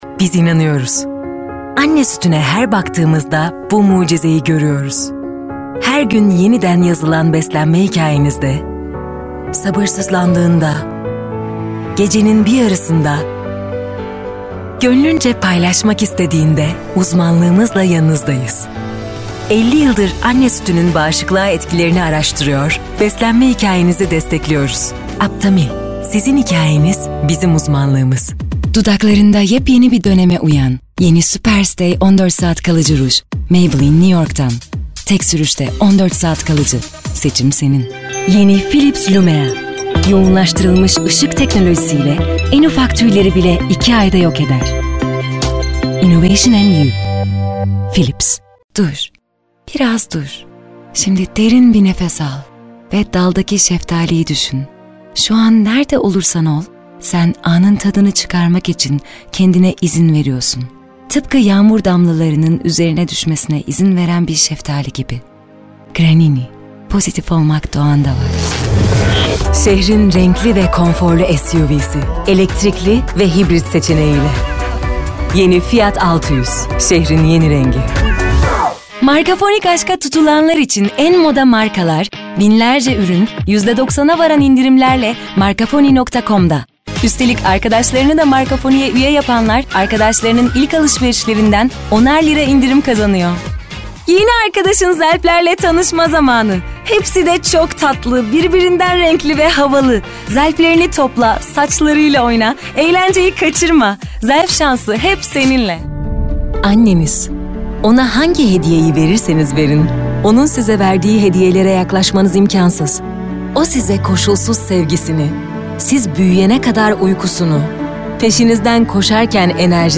Kadın
DEMO SESLERİ
Canlı, Eğlenceli, Güvenilir, Havalı, Seksi, Masalsı, Karizmatik, Vokal, Sıcakkanlı, Film Sesi, Tok / Kalın, Genç, Dış Ses,